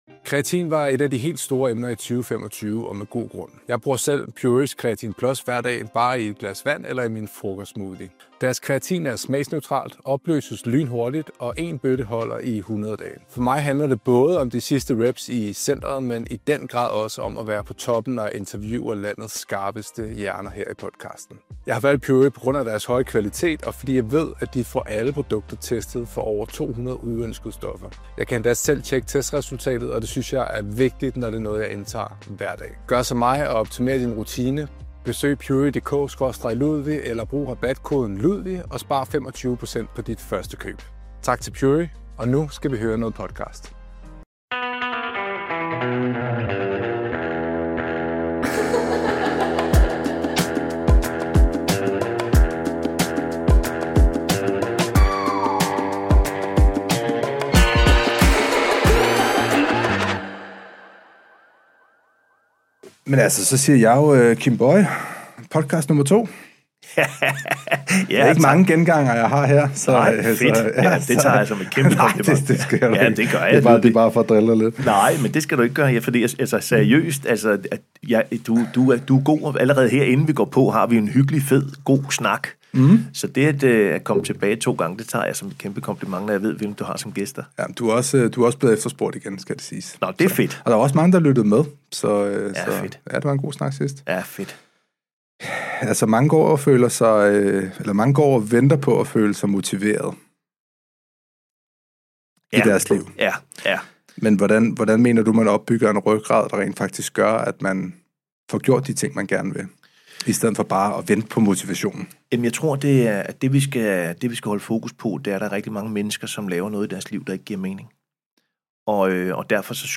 Lyt med når jeg får mig en god snak